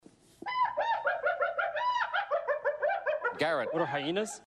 A cackle is a group of these African mammals, one of which is heard